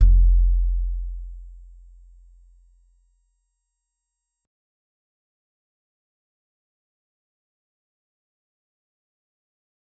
G_Musicbox-F1-mf.wav